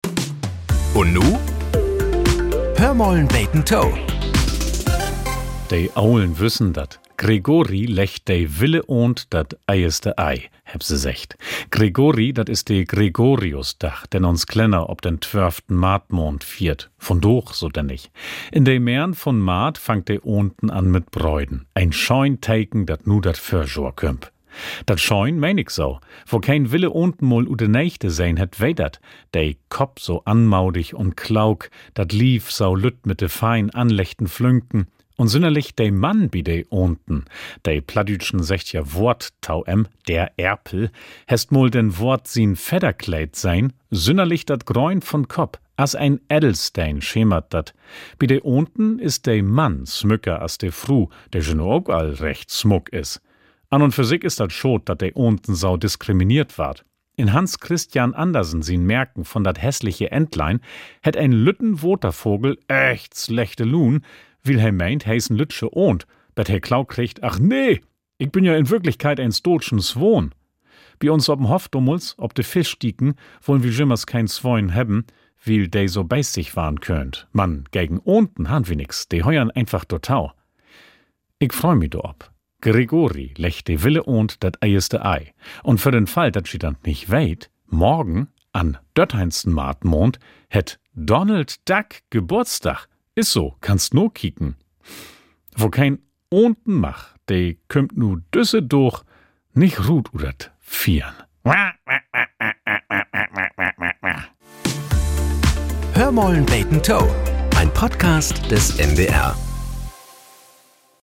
Nachrichten - 25.04.2025